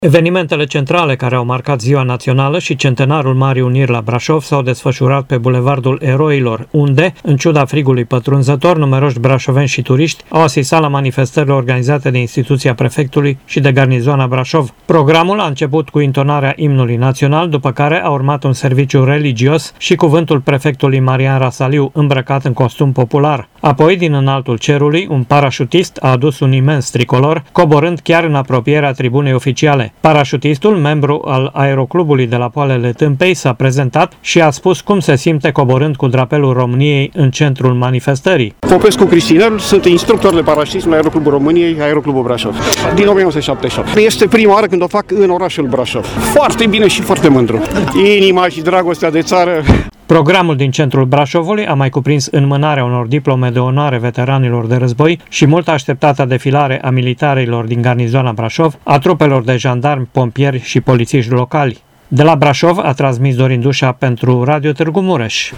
Mii de persoane între care şi foarte mulţi copii au asistat la parada militară de 1 Decembrie: